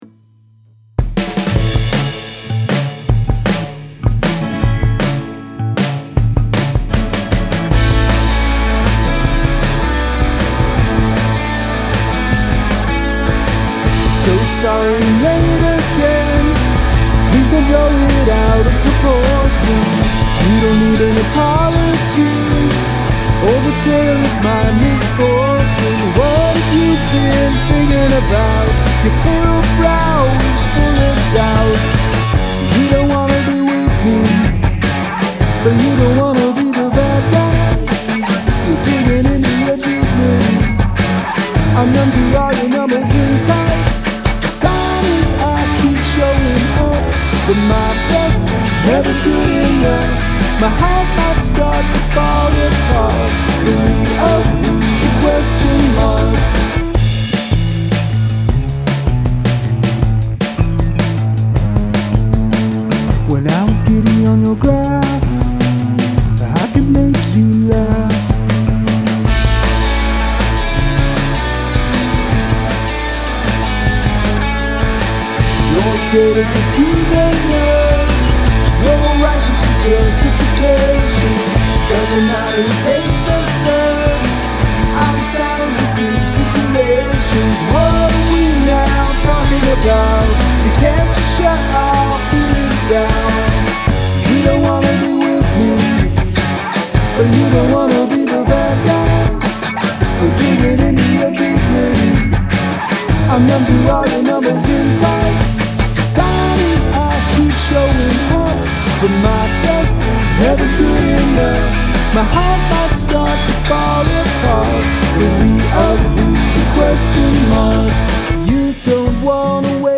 this has a lot of good in it, I particularly like the twin synth / guitar lead work.